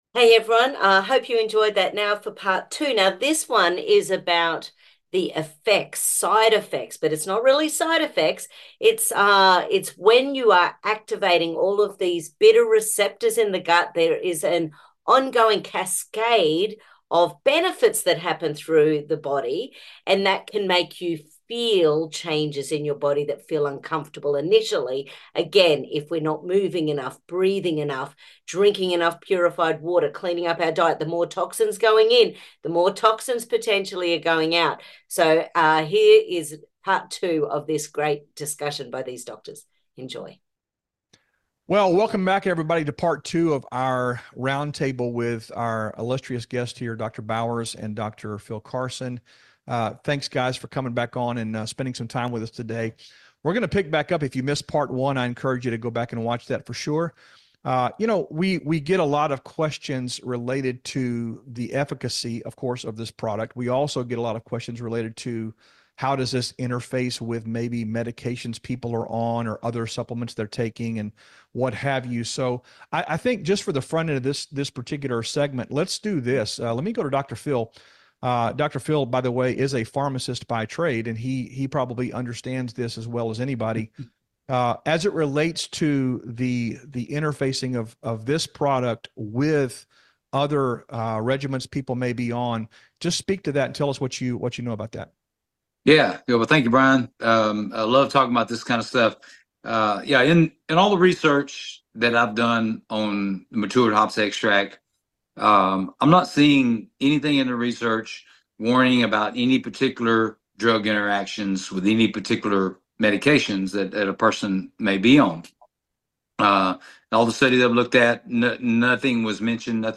Part 2: Roundtable Doc Discussion about pHix... Matured Hops Bitter Extracts part 2...